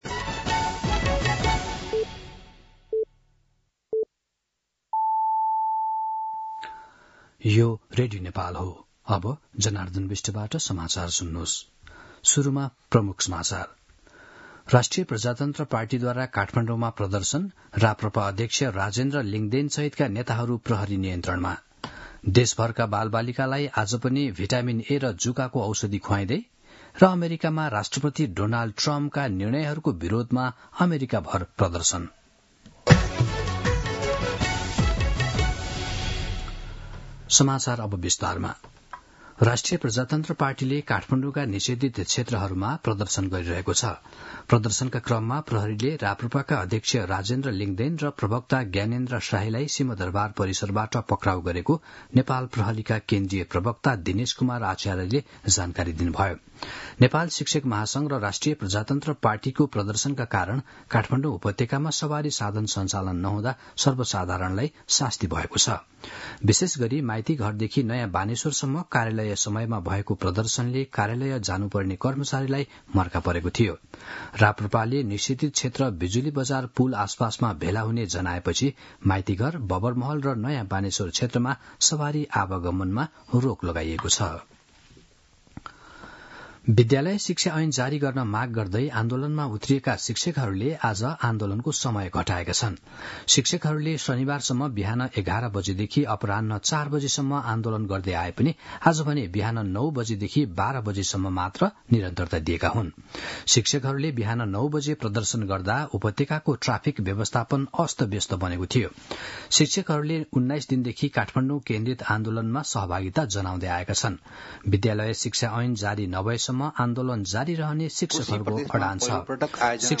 दिउँसो ३ बजेको नेपाली समाचार : ७ वैशाख , २०८२
3-pm-Nepali-News-01-07.mp3